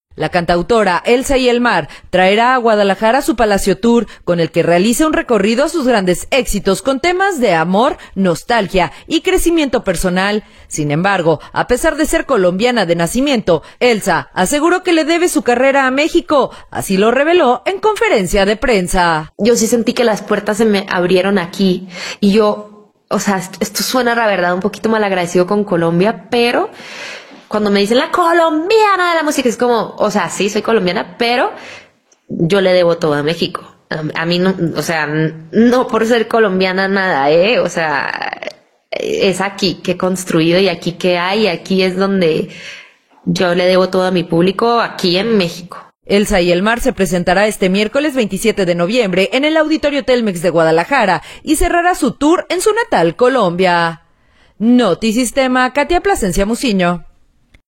La cantautora Elsa y Elmar traerá a Guadalajara su “Palacio Tour”, con el que realiza un recorrido a sus grandes éxitos con temas de amor, nostalgia y crecimiento personal. Sin embargo, a pesar de ser colombiana de nacimiento, Elsa aseguró que le debe su carrera a México, así lo reveló en conferencia de prensa.